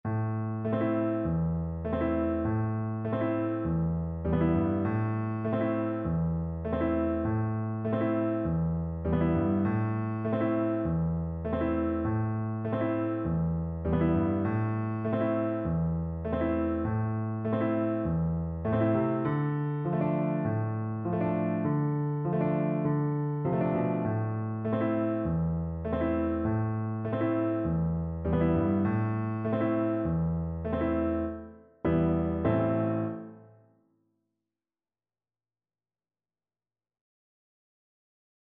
Moderato